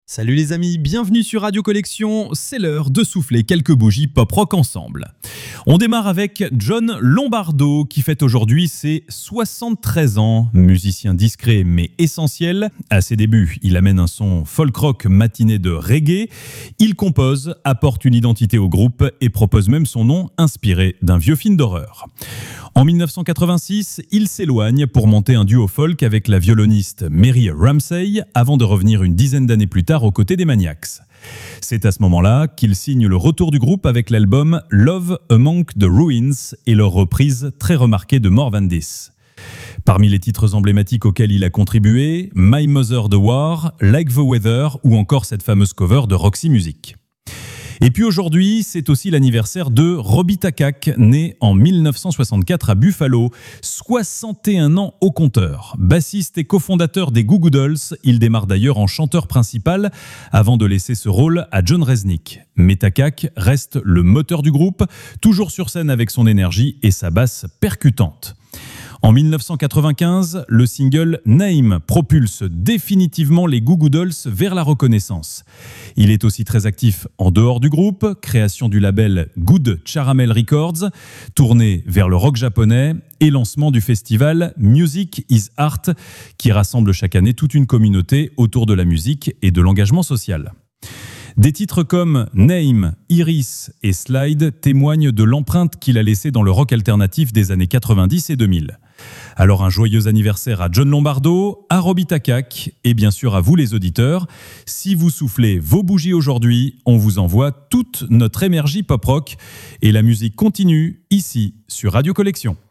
Vous écoutez la chronique Pop Rock de Radio Collection, la webradio gratuite et sans pub qui diffuse les plus grands classiques et les nouveautés en qualité Hi-Fi.